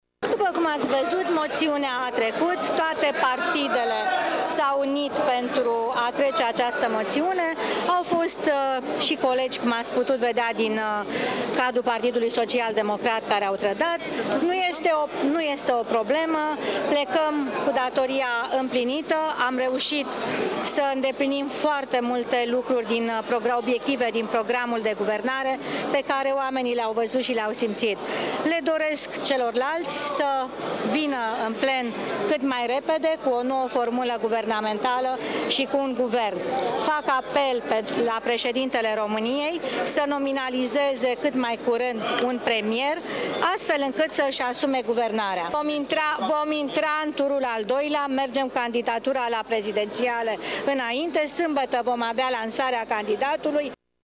Premierul Viorica Dăncilă a făcut apel la președintele României să nominalizeze cât mai curând un premier: